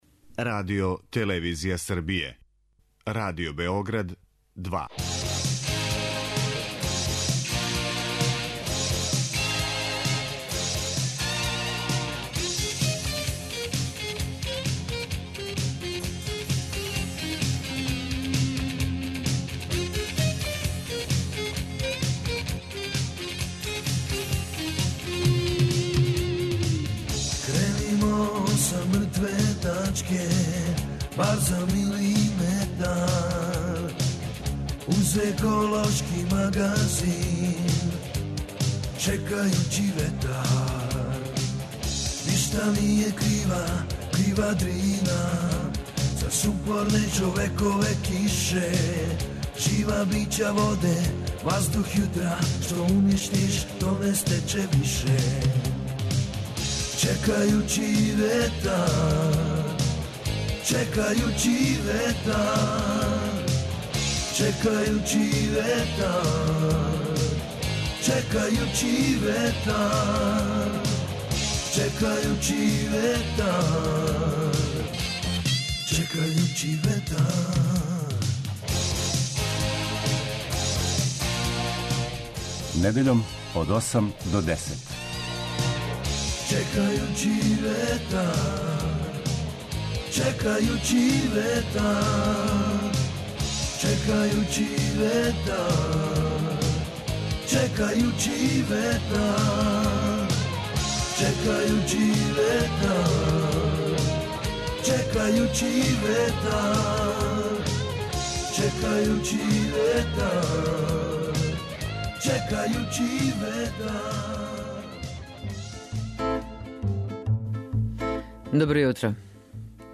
Чекајући ветар - еколошки магазин